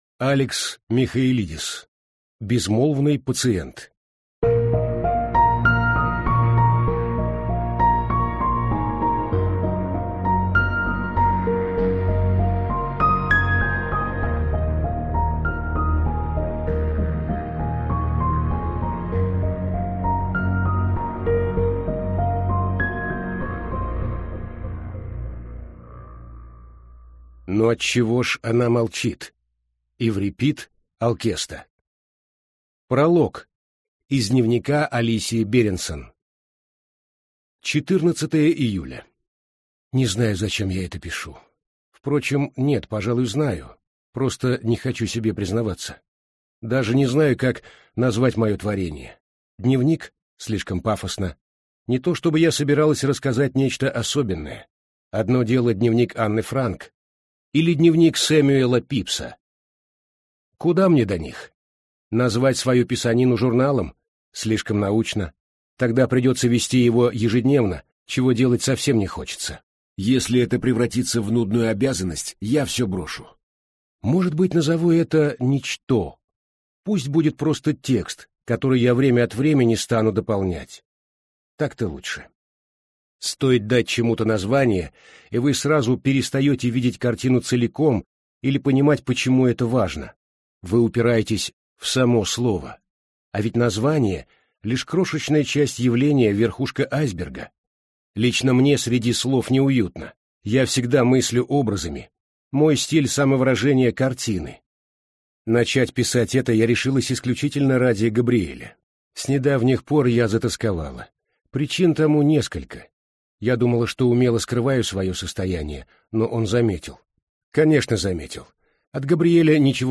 Прослушать фрагмент аудиокниги Безмолвный пациент Алекс Михаэлидес Произведений: 1 Скачать бесплатно книгу Скачать в MP3 Вы скачиваете фрагмент книги, предоставленный издательством